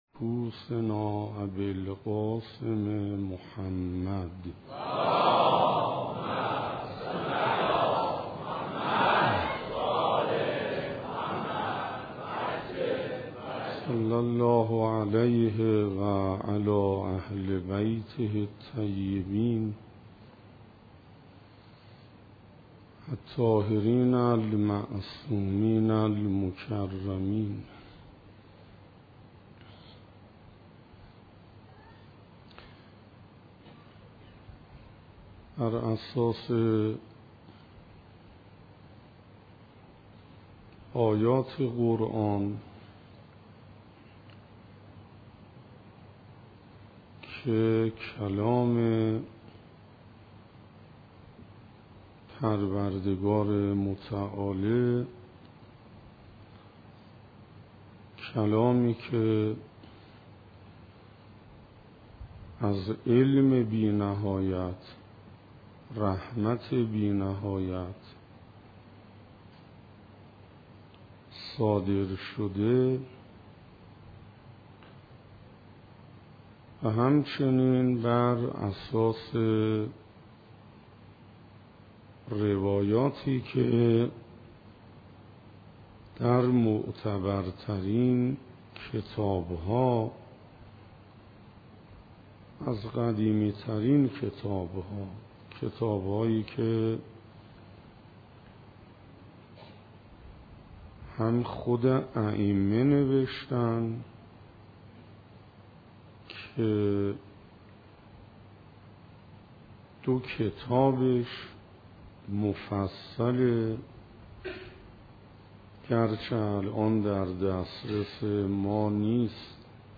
در این بخش، اولین جلسه از سلسله مباحث «نور معرفت» در کلام حجت الاسلام استاد حسین انصاریان را به مدت 62 دقیقه با سالکان طریق معنویت به اشتراک می گذاریم.